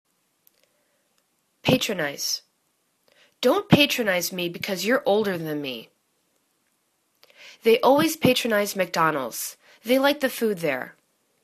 pat.ron.ize     /'patrəni:z/    v